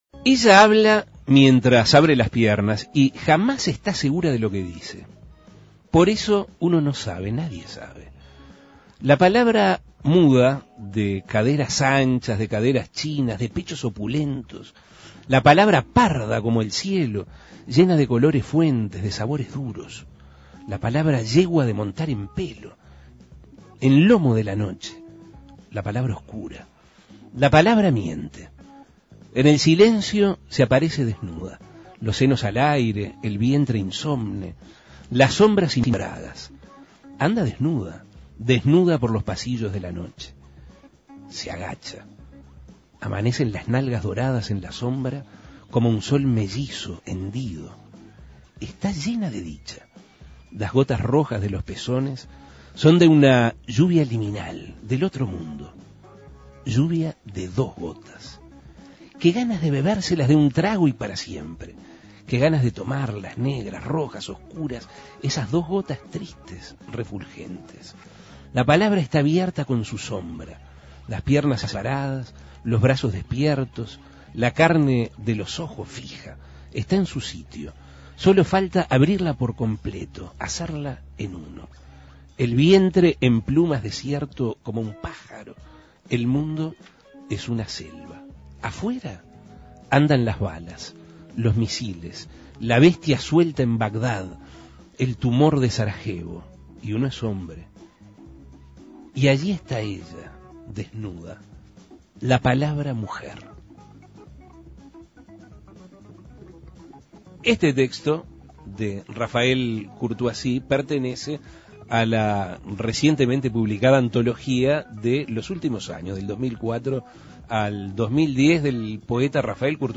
Asuntos Pendientes entrevistó a Rafael Courtoisie, escritor y periodista. Hizo un recorrido por parte de su vida y sus obras, destacando las características más importantes del arte de la poesía.
Entrevistas La palabra muda de Courtoisie Imprimir A- A A+ Asuntos Pendientes entrevistó a Rafael Courtoisie, escritor y periodista.